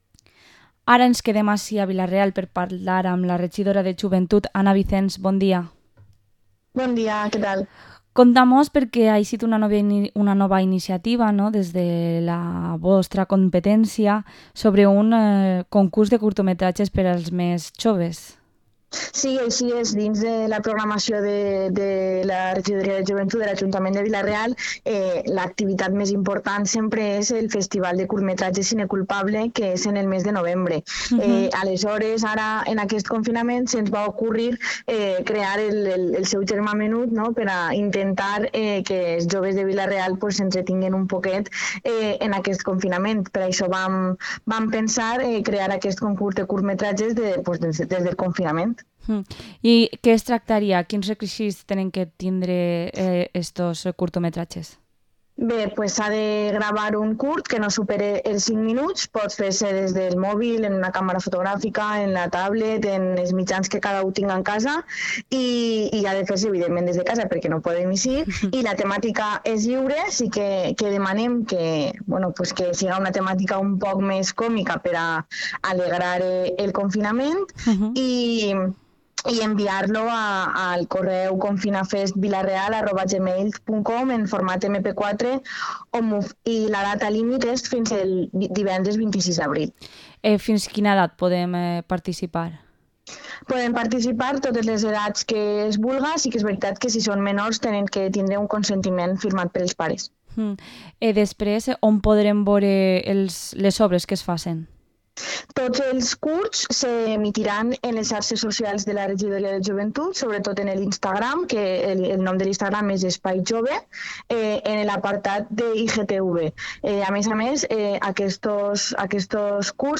Entrevista a la concejala de Juventud de Vila-real, Anna Vicens